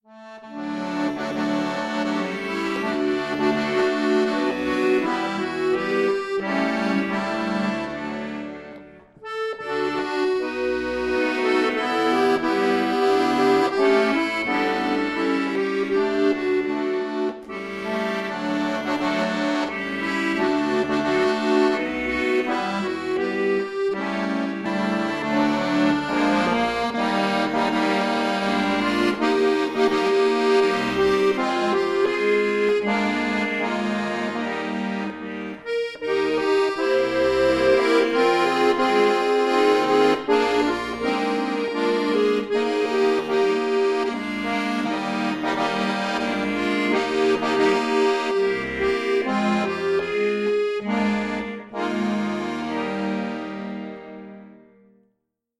Traditionelles Weihnachtslied
neu arrangiert für Akkordeon solo
Christmas Carol